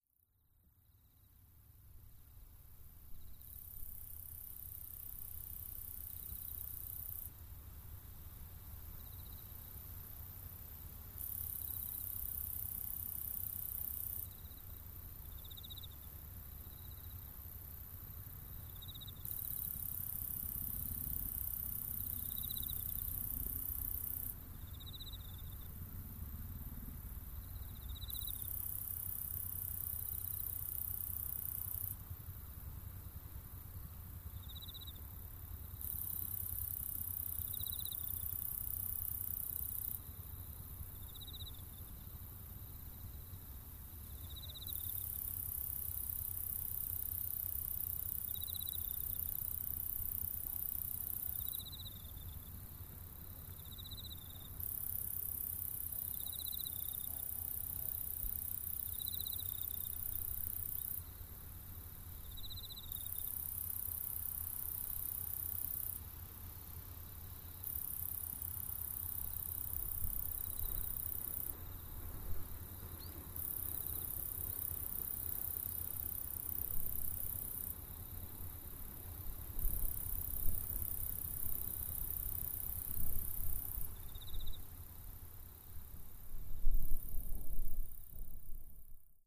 ポッドキャストにもアップしているウスイロササキリの鳴き声を使って、レートを変えてMP3圧縮しスペクトログラムで比較してみた。
カセットテープなみといったところか。
▲MP3圧縮　96kbps 48kHz　ローパスフィルターは15kHz付近・・・